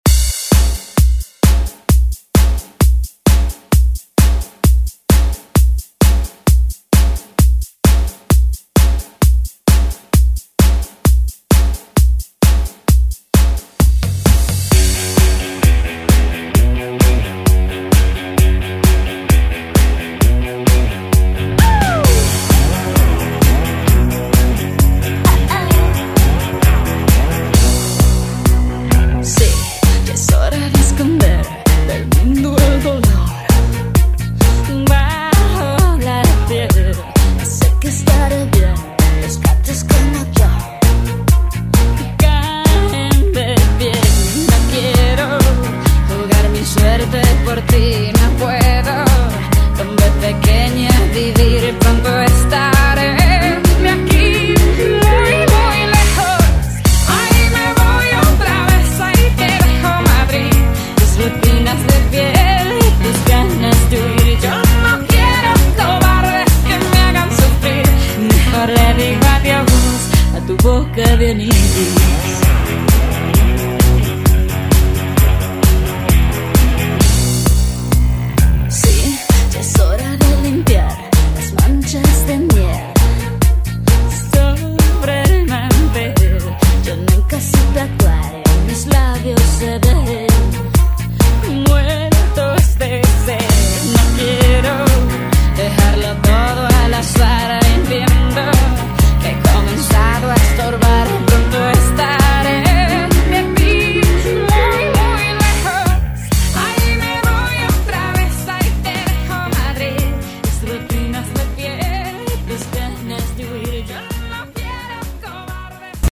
2000s Throwback Pop Rock Music Extended ReDrum Clean 105 bpm
Genres: RE-DRUM , ROCK
Clean BPM: 105 Time